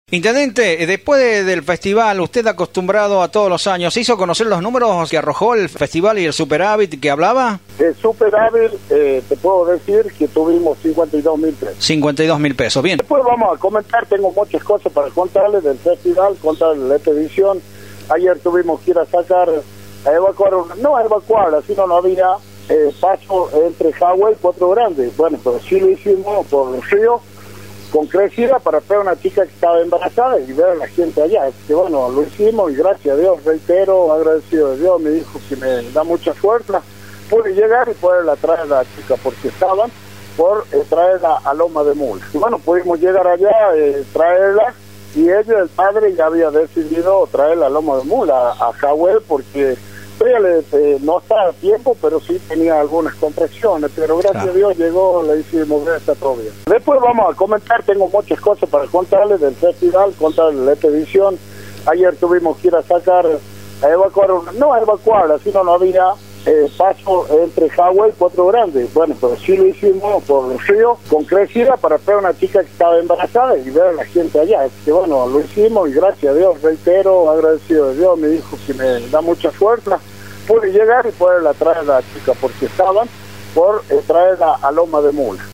Ariel Oviedo sobre el festival por Radio 7 de Villa Unión Ariel Oviedo, intendente de Vinchina, por Radio 7 de Villa Unión
ariel-oviedo-sobre-el-festival-por-radio-7-de-villa-unic3b3n.mp3